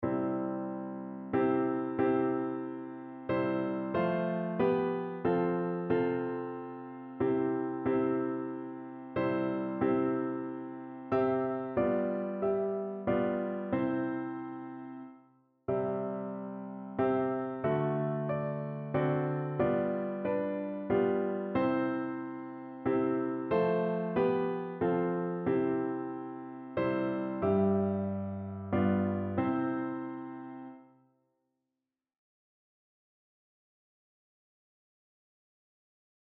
Notensatz (4 Stimmen gemischt)